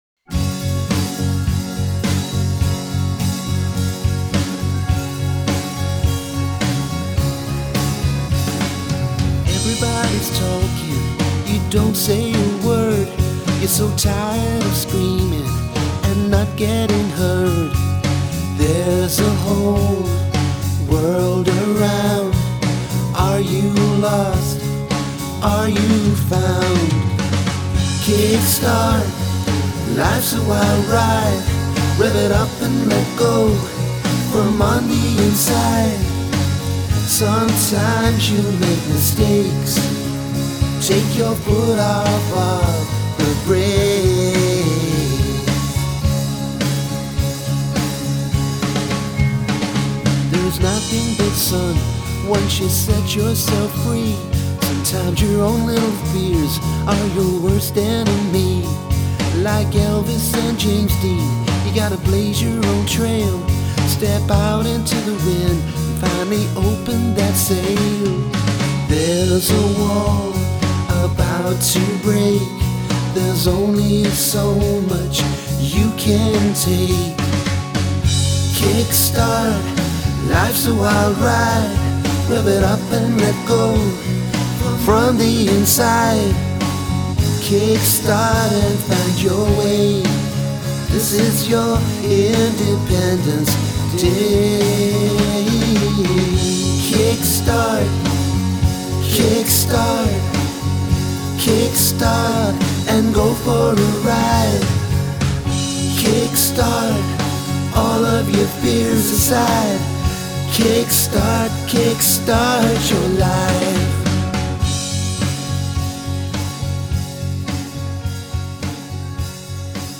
I love the drumming the most.
• I really liked the guitar playing in this song.
The bass was a little too lite for my taste.
• The song was laid back and mellow.